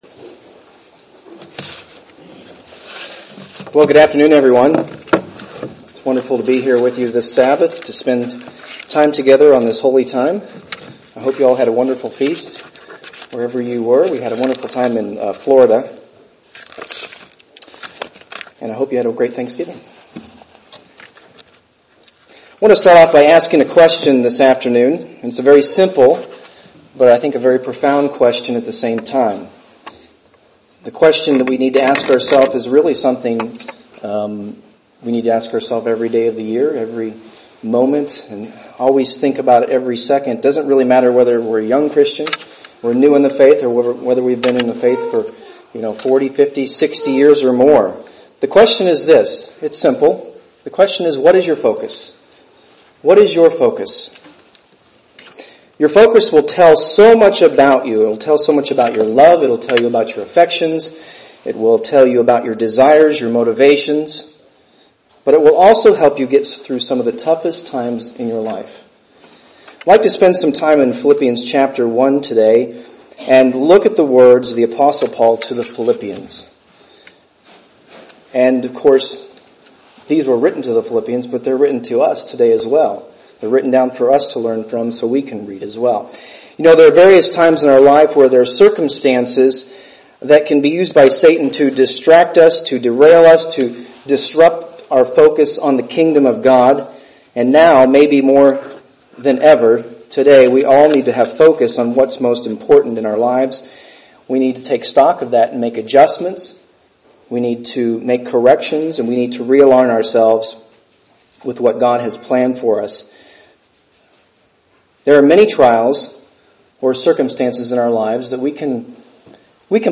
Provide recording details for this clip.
Given in Kansas City, KS